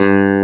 CLAV2SFTG2.wav